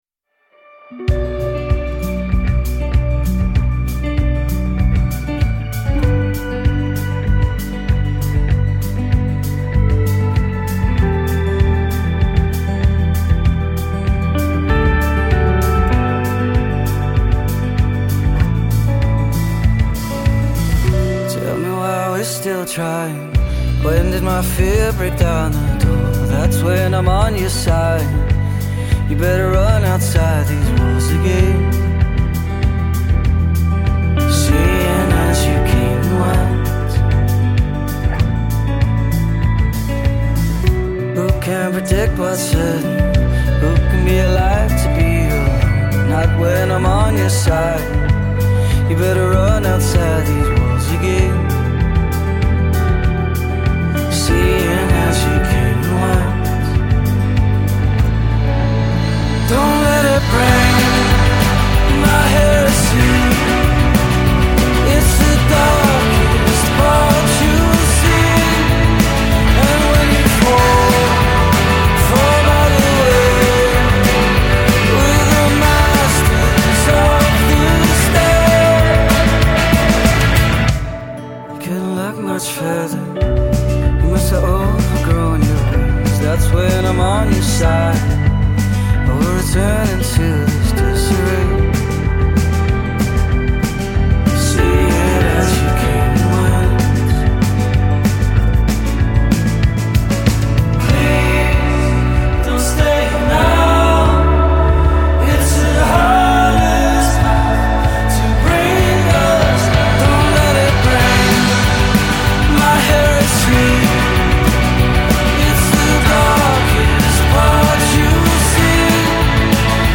Brooding Liverpool, UK quintet